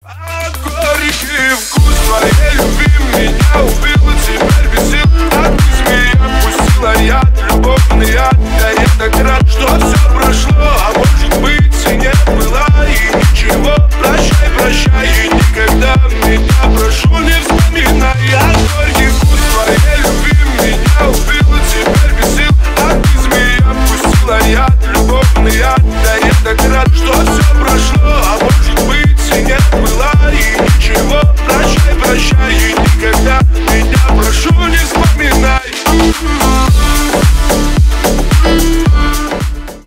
Ремикс
клубные # громкие # кавказские